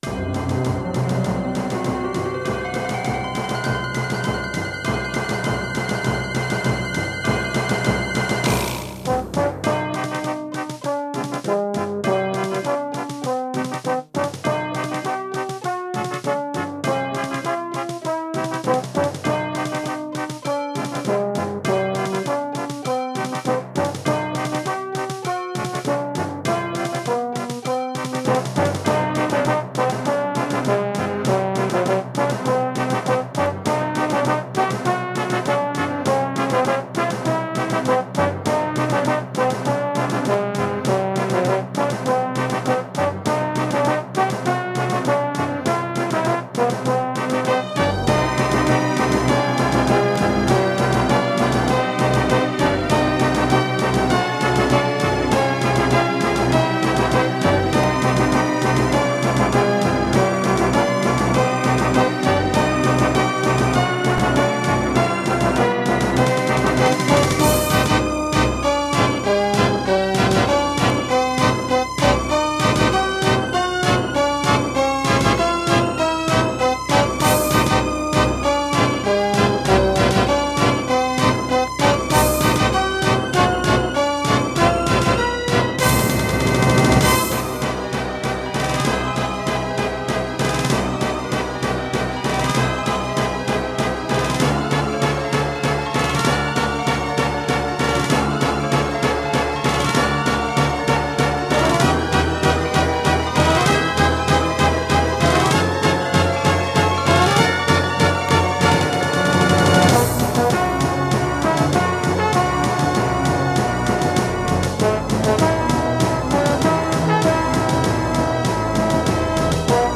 Terratec WaveSystem SIWT-1
* Some records contain clicks.